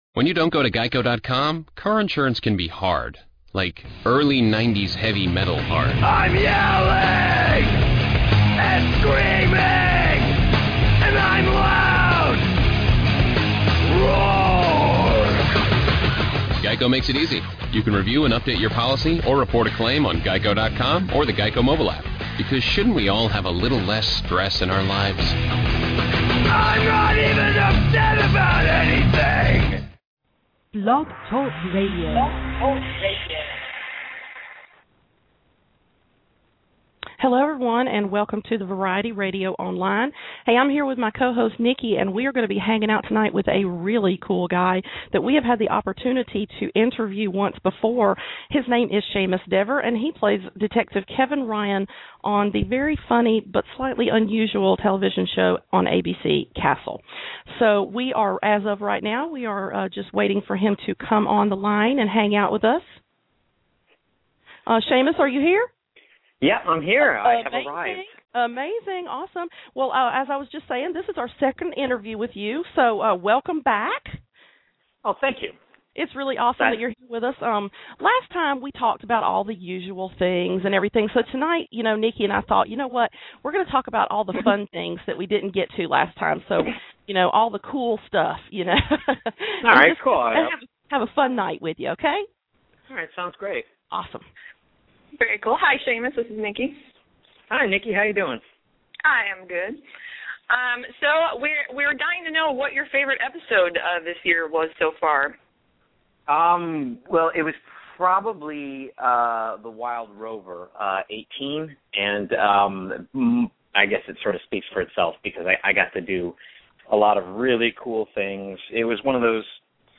Seamus Dever 2013 Interview
We will be taking questions, and if you listen live you will have an opportunity to call in and ask Seamus your own question.